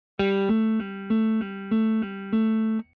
▶Automatic Hammer On & Pull Off：ハンマリング・オン / プルオフを自動で適用